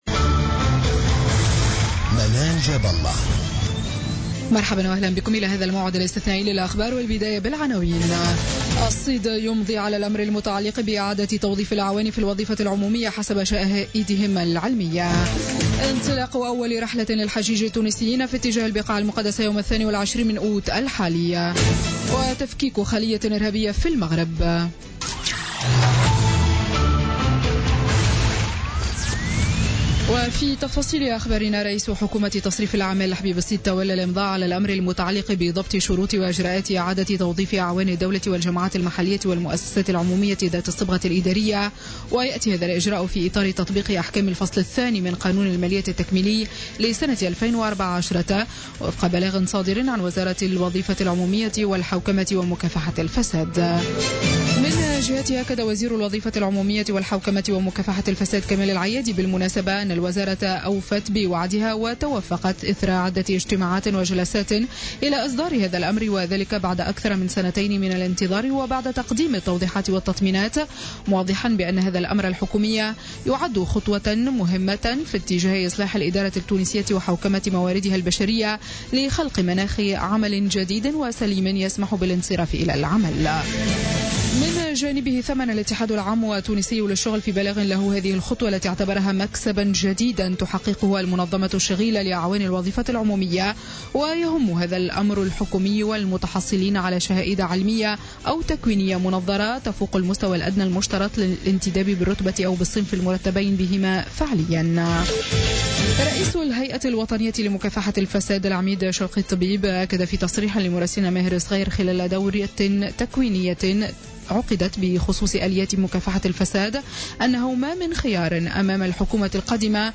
نشرة أخبار الثامنة مساء ليوم الثلاثاء 16 أوت 2016